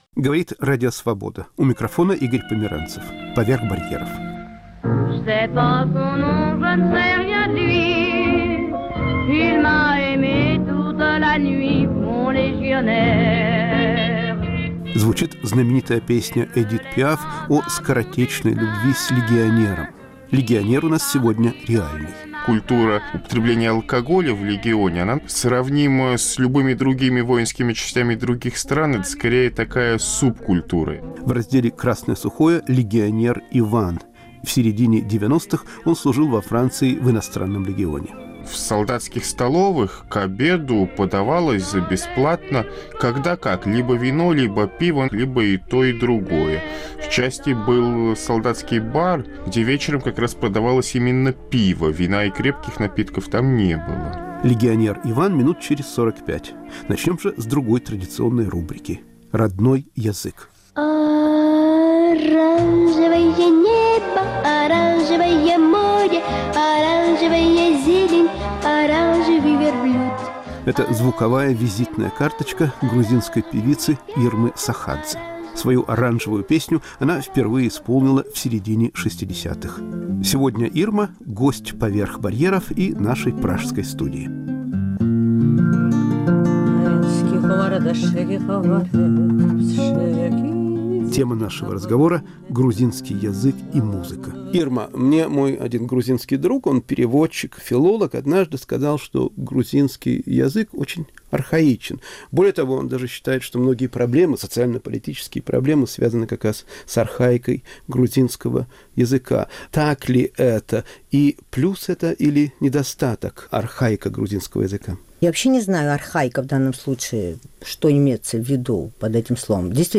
В разделе "Родной язык" рассказывает и поёт грузинская певица Ирма Сохадзе*** Сельскохозяйственная православная община в Михново (Литва)*** "Красное сухое": что и как пьют в "Иностранном легионе" (Франция)